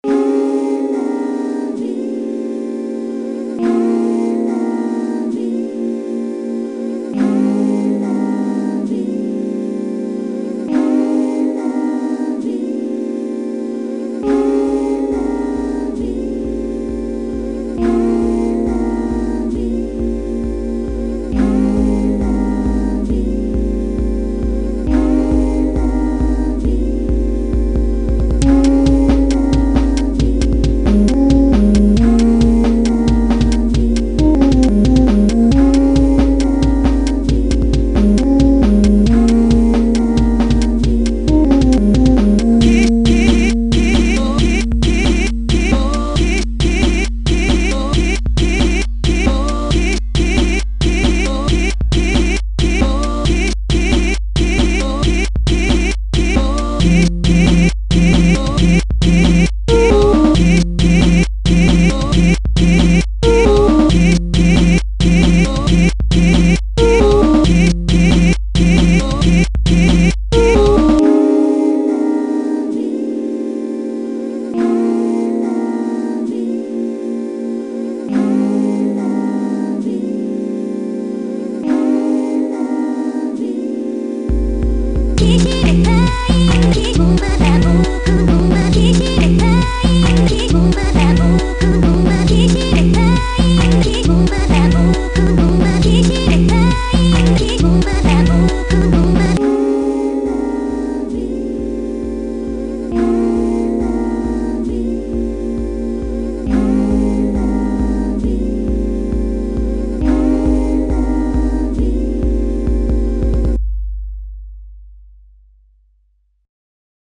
Amiga sans distortion is a change for me.
The vocal samples worked well with the distortion smile
The distortion give it a cool lofi feel though.